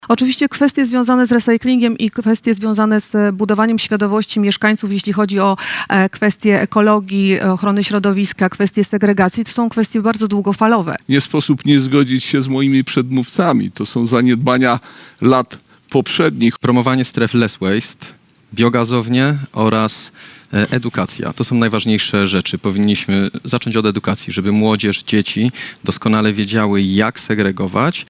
Wczoraj (04.04) w auli Uniwersytetu Bielsko-Bialskiego odbyła się debata z udziałem kandydatów na prezydenta Bielska-Białej.